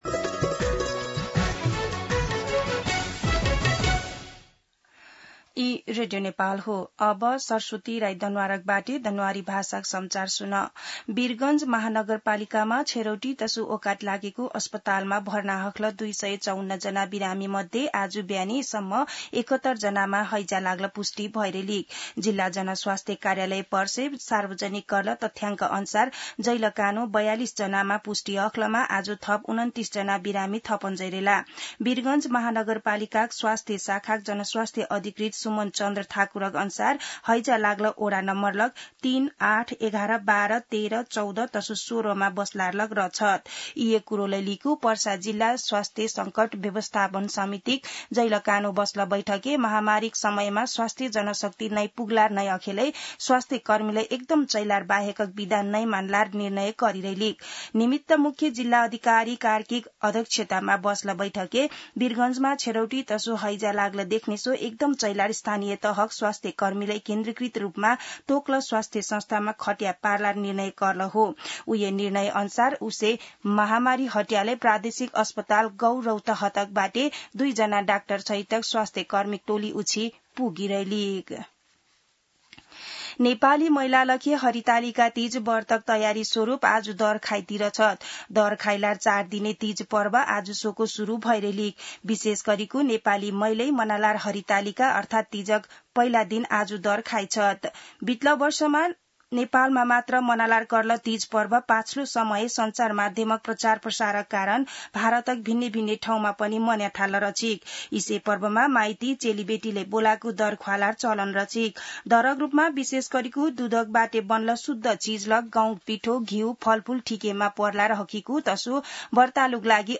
An online outlet of Nepal's national radio broadcaster
दनुवार भाषामा समाचार : ९ भदौ , २०८२
Danuwar-News-5-9.mp3